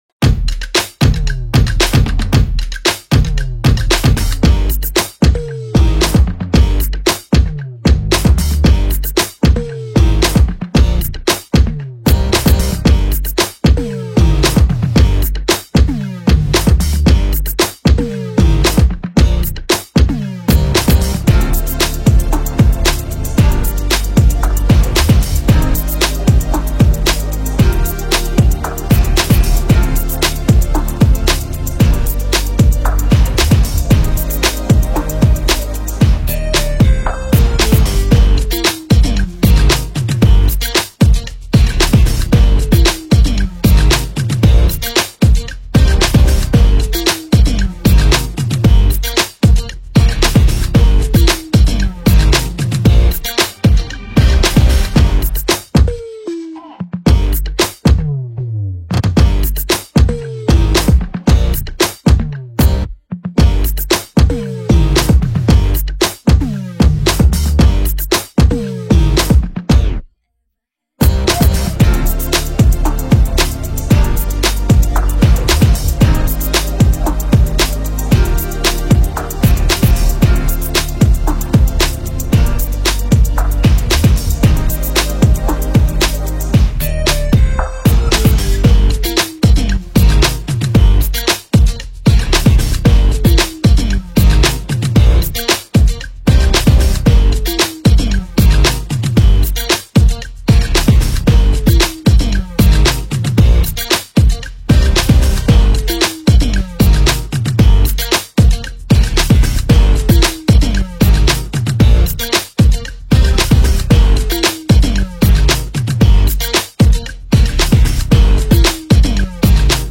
Near Official Instrumental